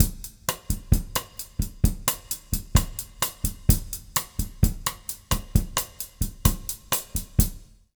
130BOSSA04-R.wav